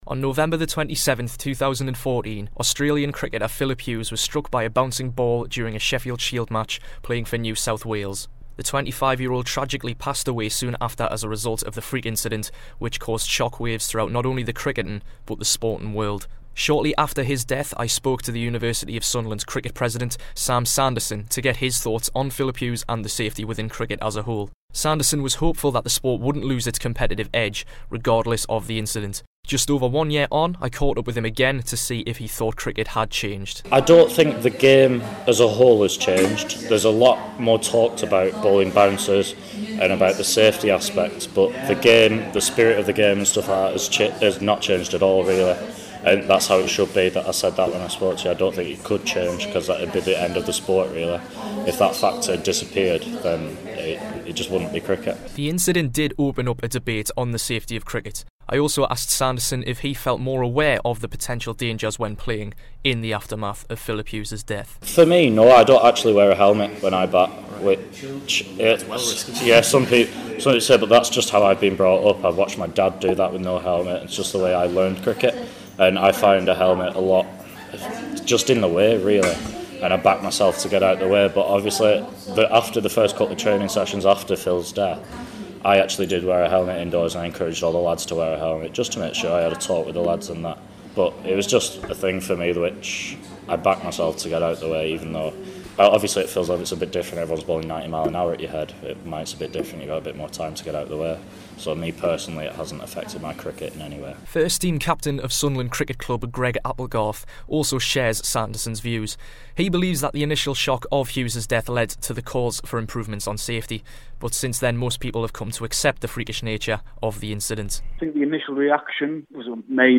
I spoke with Sunderland cricketers